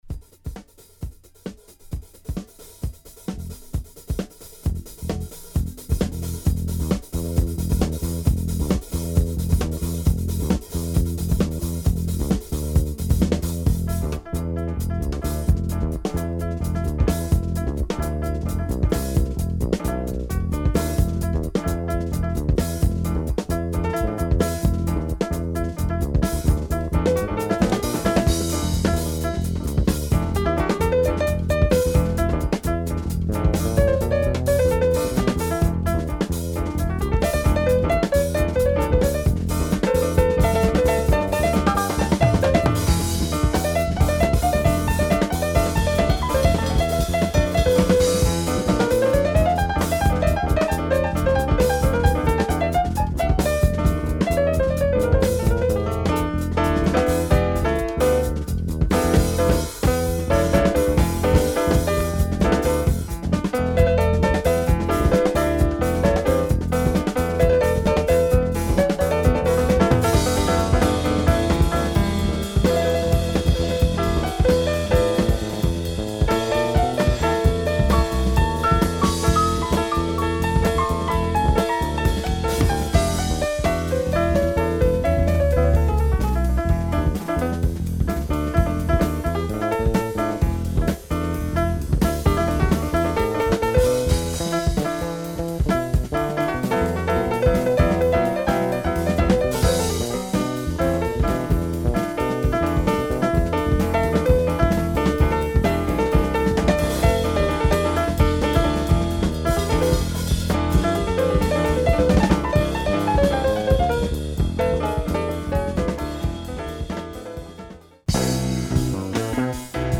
Groovy jazz fusion